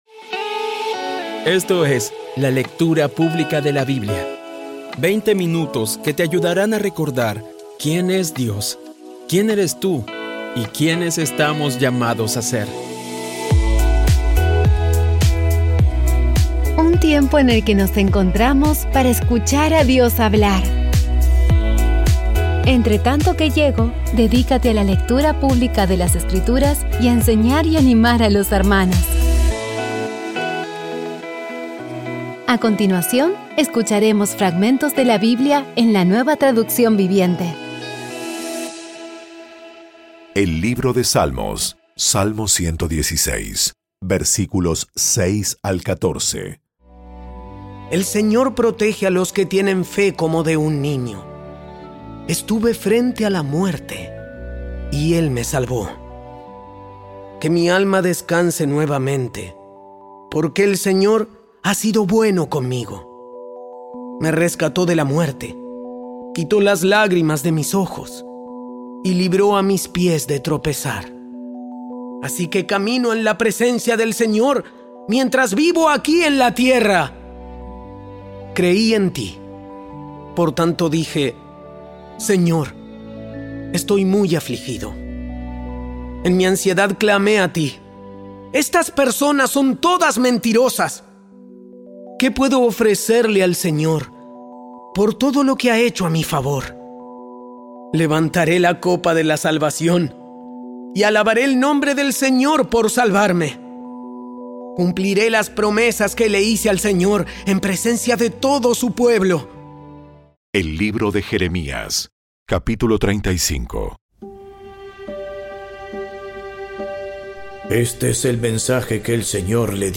Audio Biblia Dramatizada Episodio 289
Poco a poco y con las maravillosas voces actuadas de los protagonistas vas degustando las palabras de esa guía que Dios nos dio.